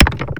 gibstone3.wav